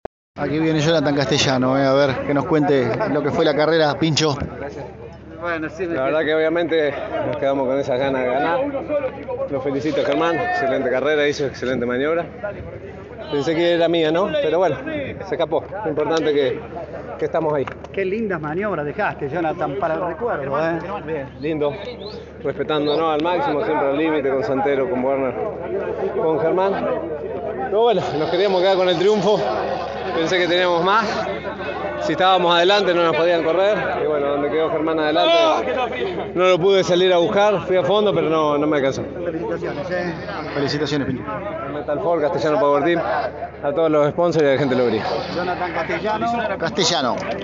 El oriundo de Lobería pasó por los micrófonos de Pole Position e hizo una análisis de la competencia, donde perdió en el último relanzamiento con Germán Todino.